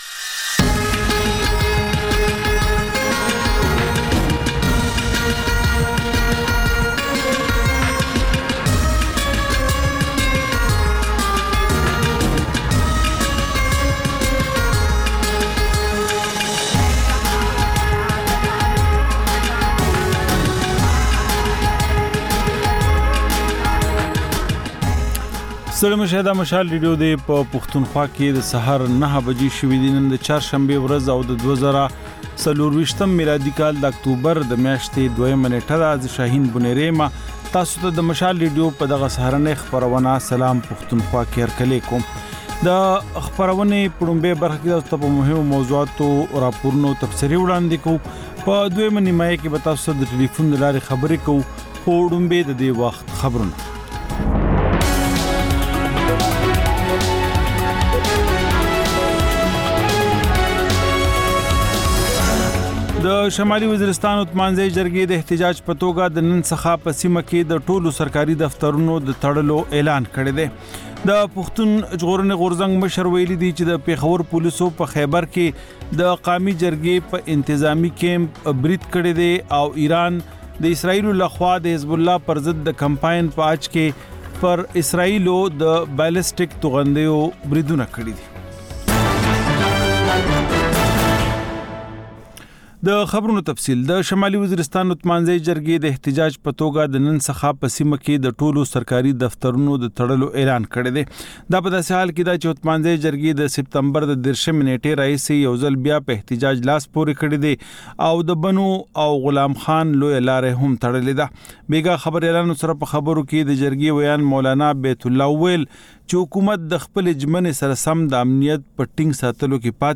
دا د مشال راډیو لومړۍ خپرونه ده چې پکې تر خبرونو وروسته رپورټونه، له خبریالانو خبرونه او رپورټونه او سندرې در خپروو.